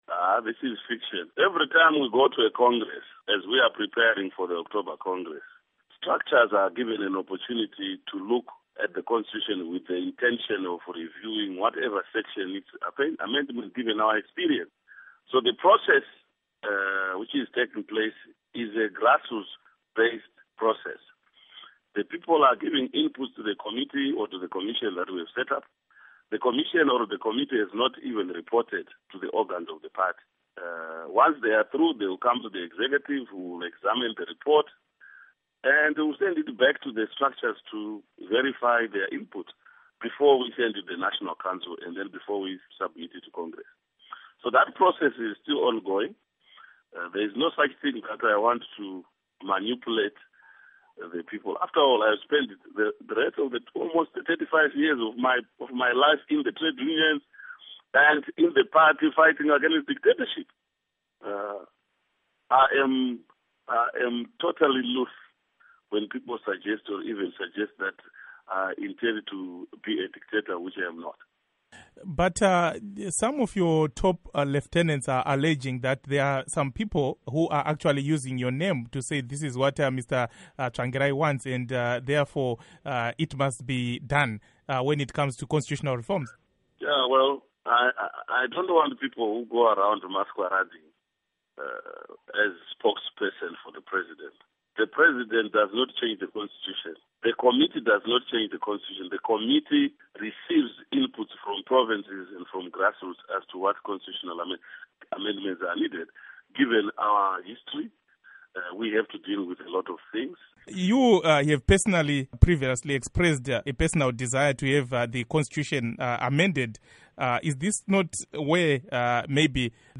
Hukuro naVaMorgan Tsvangirai